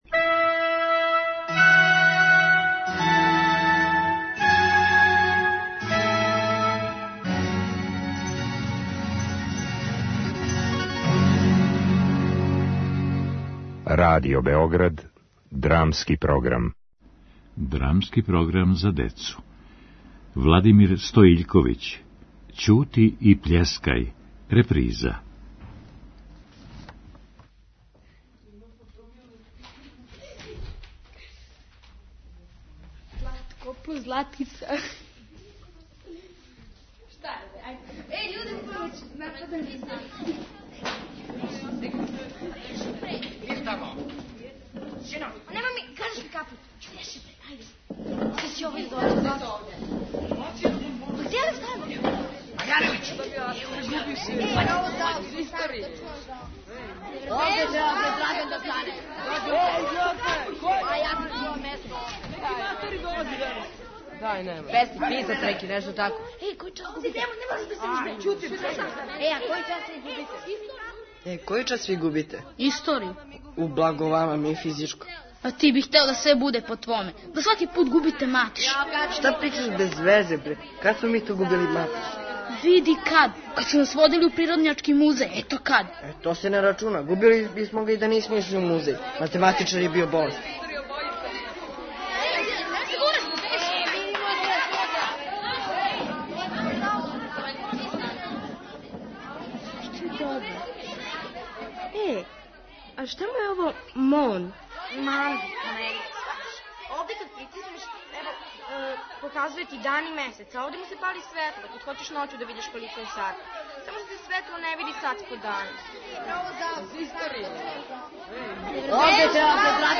Гости: средњошколци Аутор и водитељ